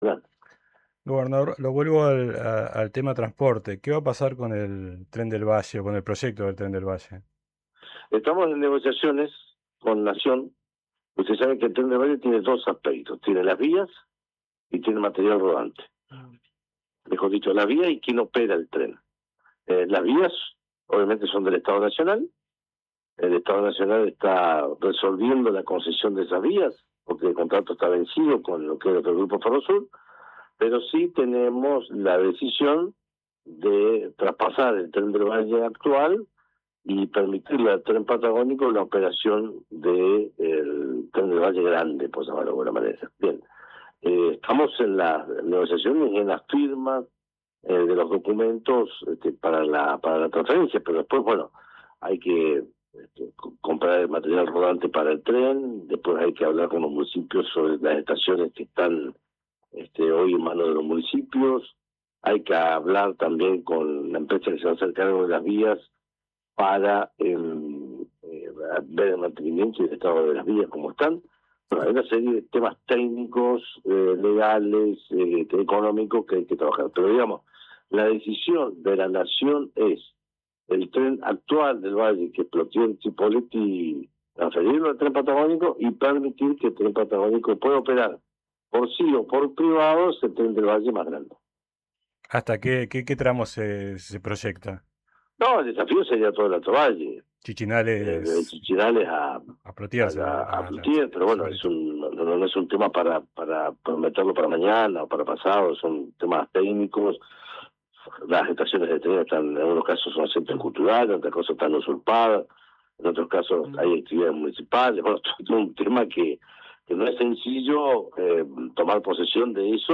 “El Tren del Valle tiene dos aspectos: tiene las vías y tiene material rodante, mejor dicho la vía y quien opera el tren”, explicó en diálogo con RÍO NEGRO Radio.